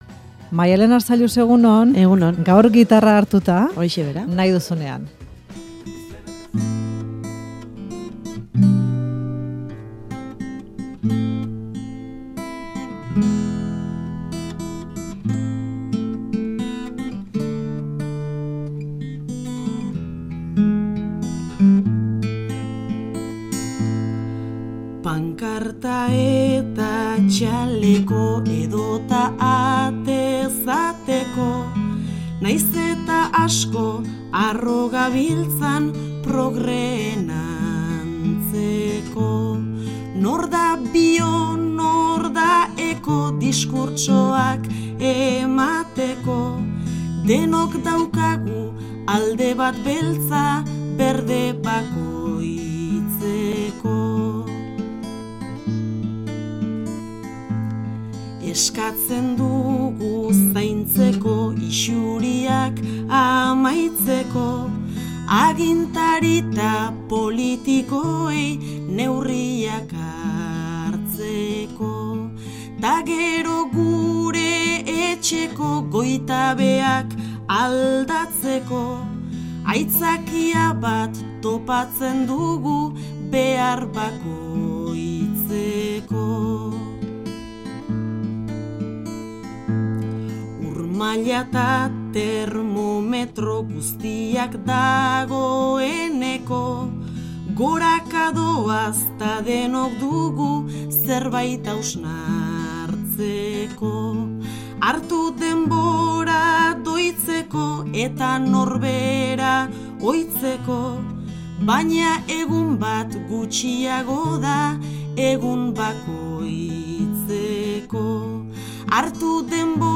gitarraz lagunduta eta abestuz osatu du. Ekologia hartu du ardatz, kantu goxo bat egiteko.